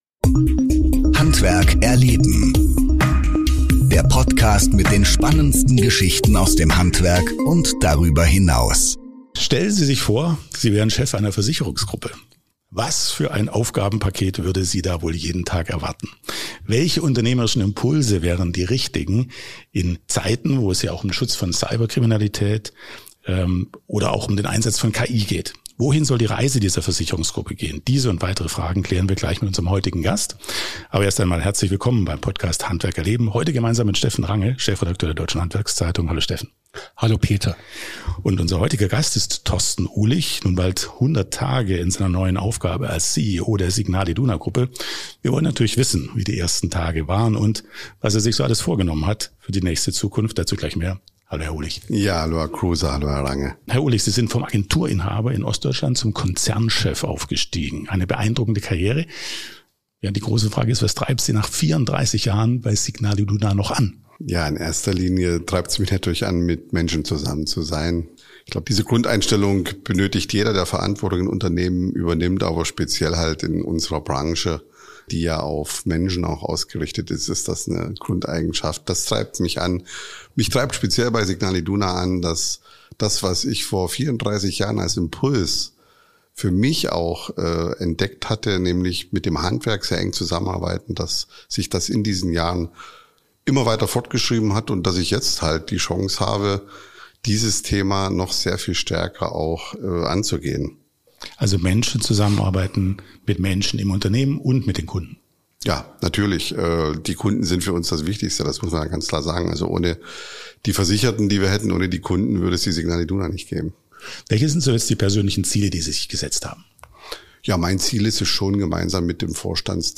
Hinweis: Dieses Interview wurde am 20.09.2025 im Rahmen des Events „Top Gründer im Handwerk“ von handwerk magazin aufgezeichnet.